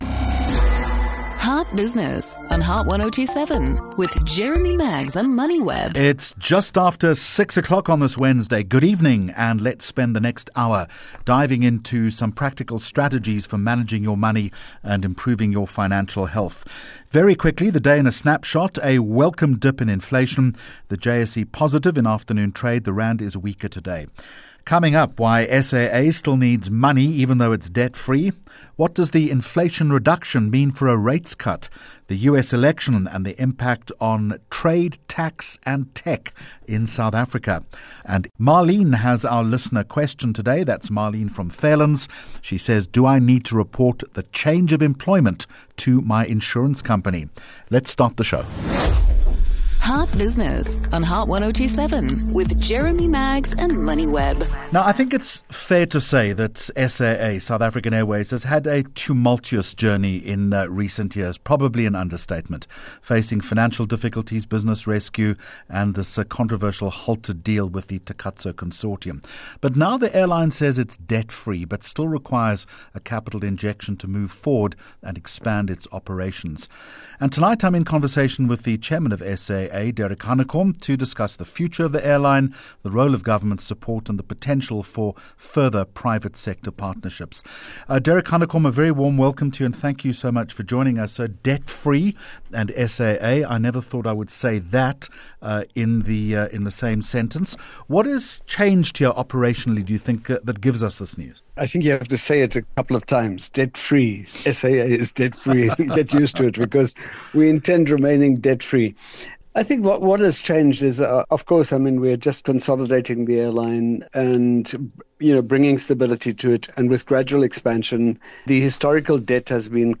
HOT TOPIC Topic: Why SAA still needs money even though its debt free Guest: Derek Hanekom – Chairperson, SAA